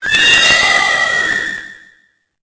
Cri_0858_EB.ogg